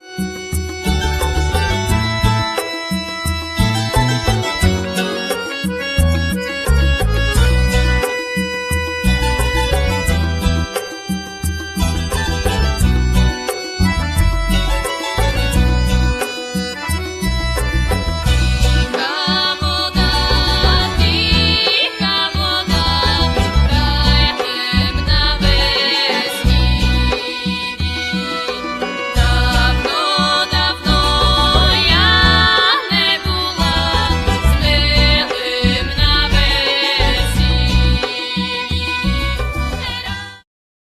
Są na nim pieśni ukraińskie, białoruskie i łemkowskie.
bębny, instrumenty perkusyjne
skrzypce